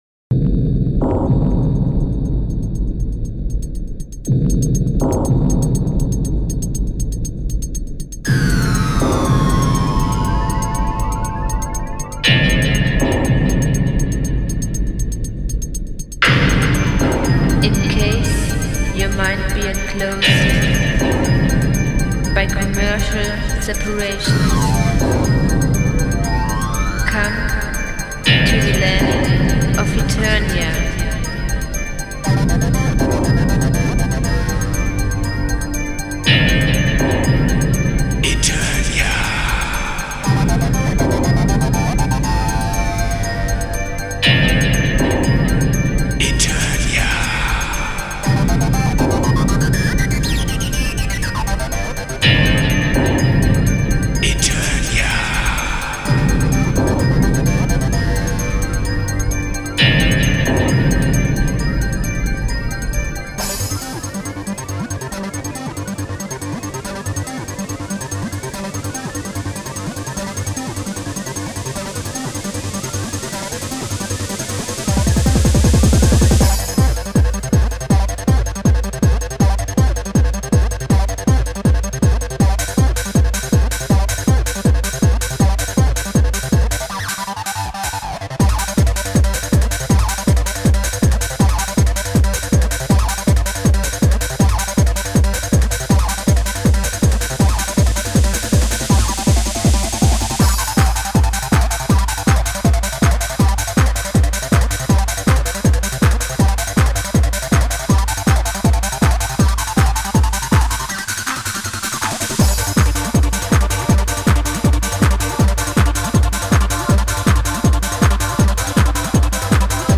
Genre: Trance.